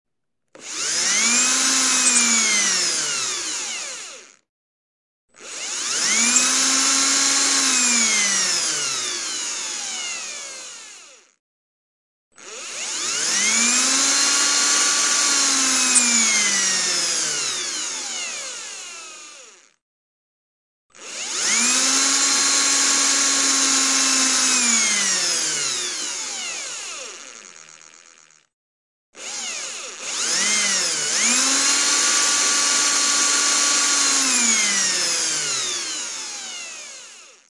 Download Drill sound effect for free.
Drill